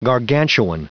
Prononciation du mot gargantuan en anglais (fichier audio)
Prononciation du mot : gargantuan